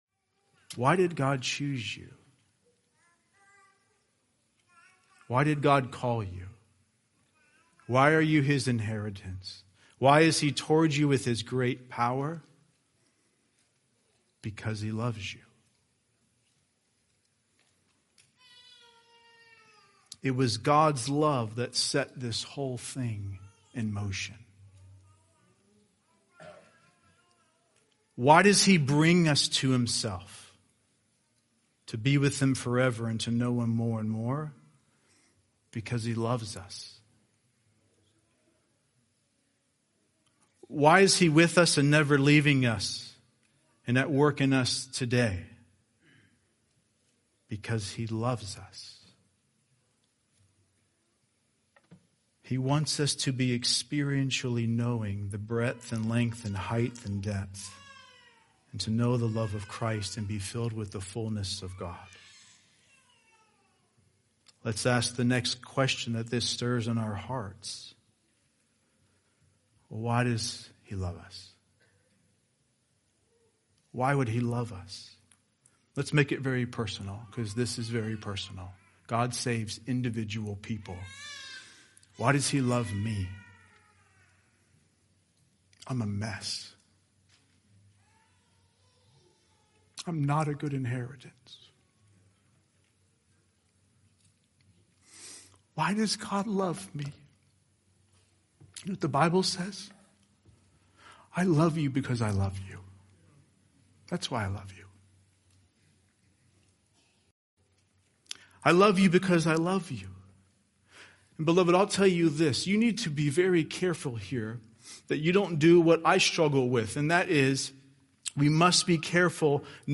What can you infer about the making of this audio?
Excerpt | 2021 Fellowship Conference| When considering the love of God, it can be difficult at times to believe that God loves His people like He says...